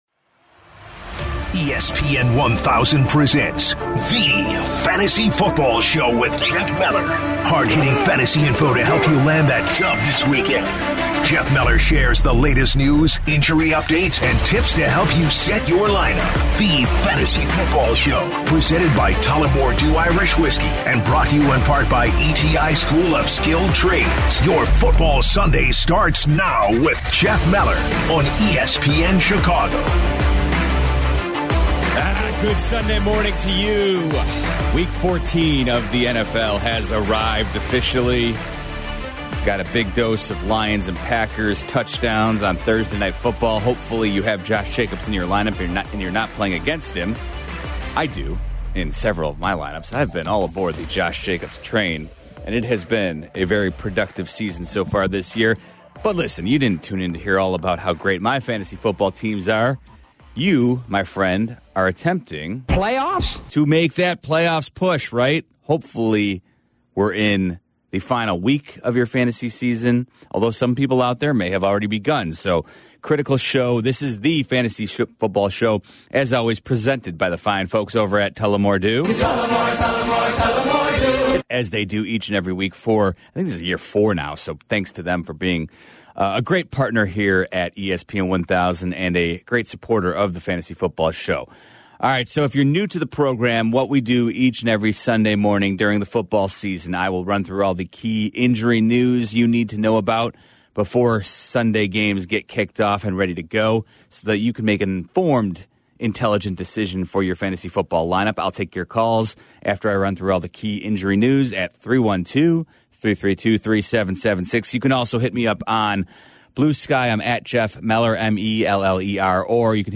answers calls as he helps guide them through their toughest start and sit questions for Week 14